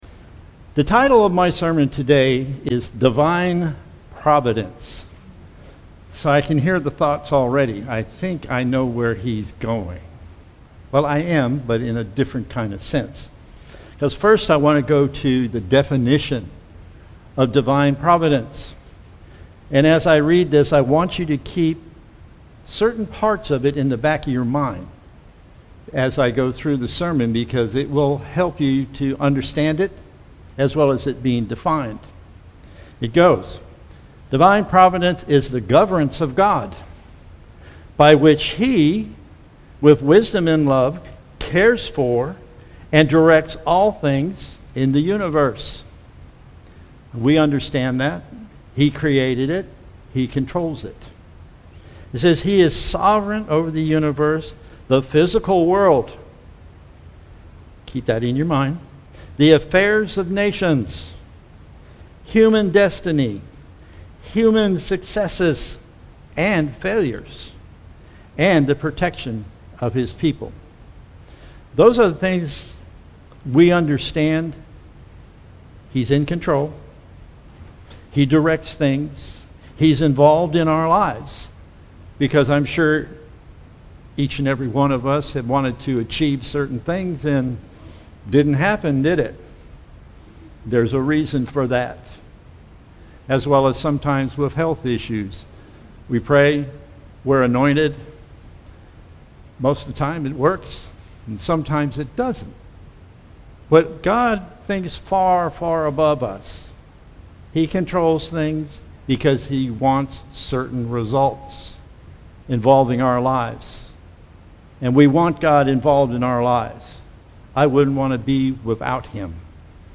Sermons
Given in Vero Beach, FL